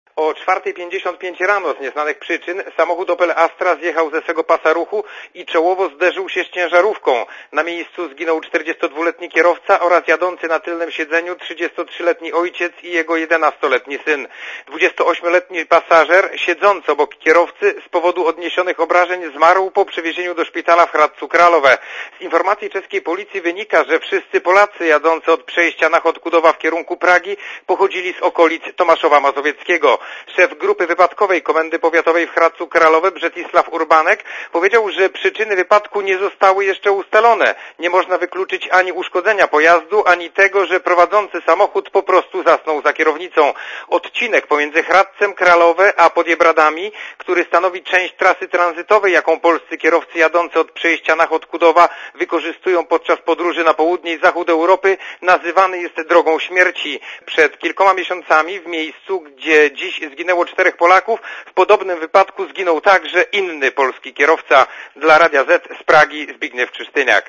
Korespondencja z Czech